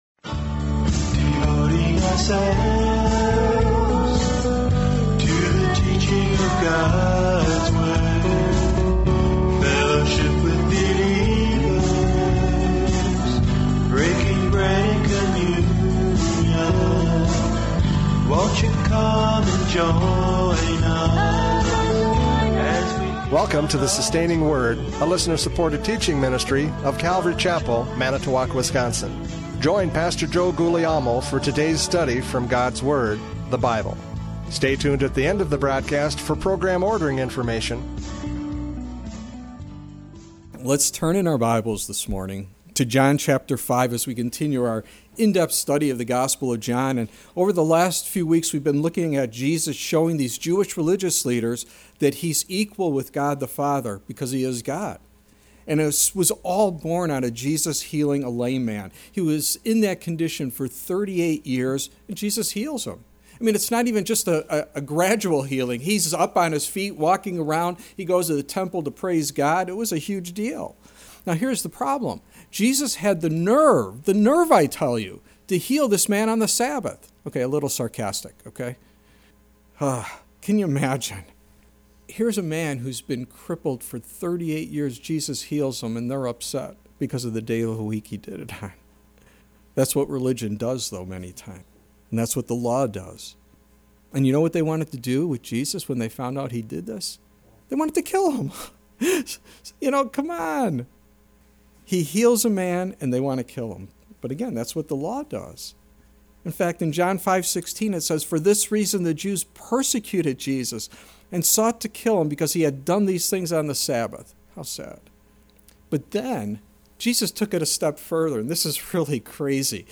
John 5:22-30 Service Type: Radio Programs « John 5:19-21 Equality in Power!